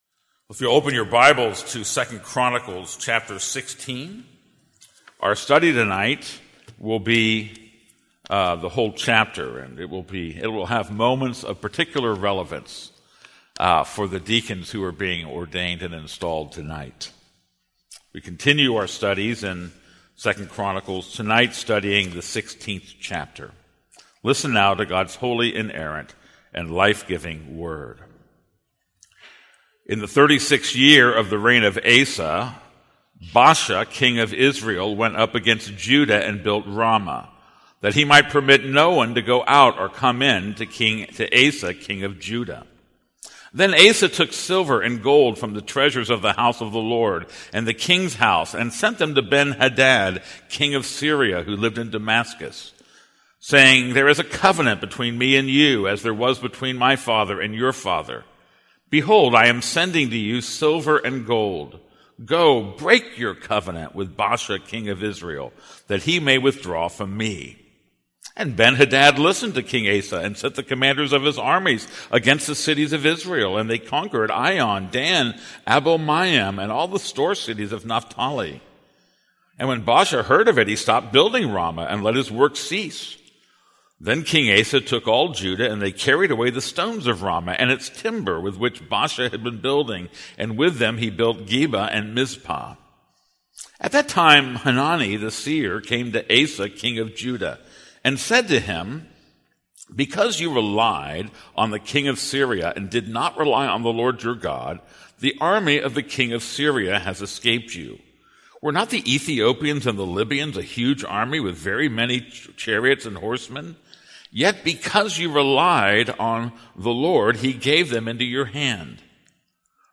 This is a sermon on 2 Chronicles 16:1-14.